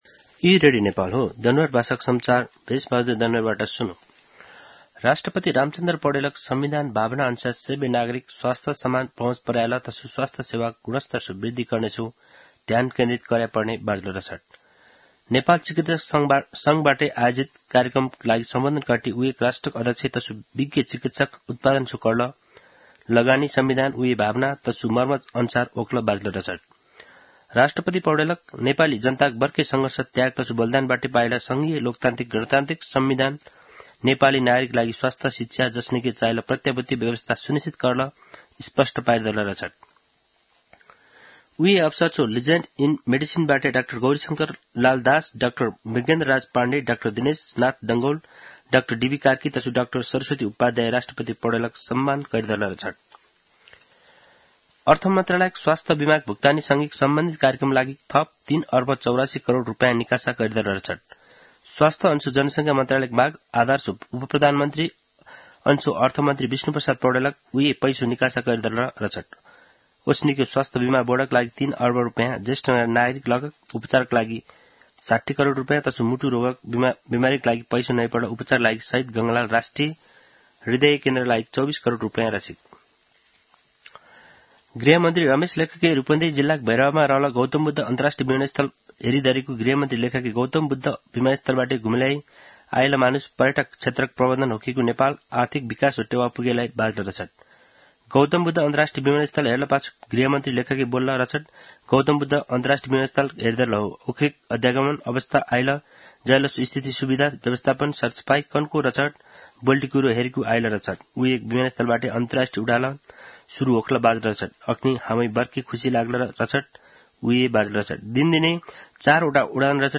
दनुवार भाषामा समाचार : २१ फागुन , २०८१
danuwar-news-1-3.mp3